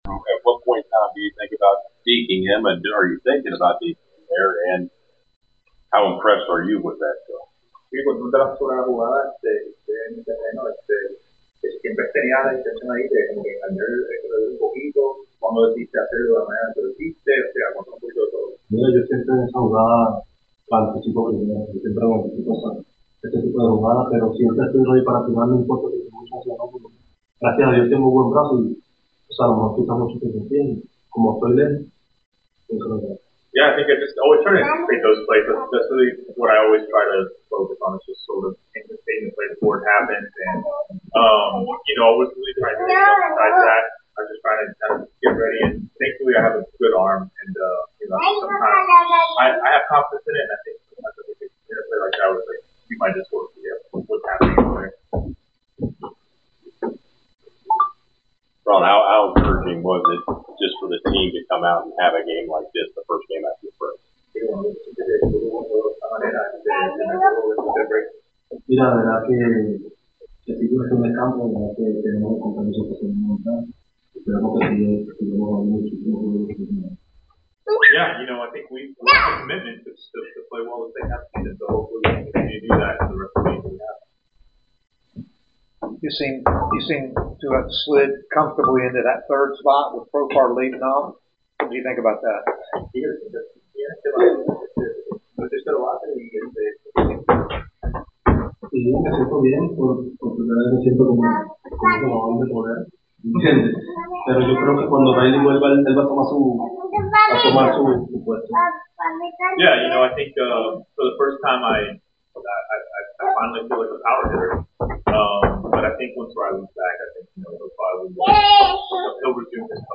Atlanta Braves Right Fielder Ronald Acuña Jr. Postgame Interview after defeating the New York Yankees at Truist Park.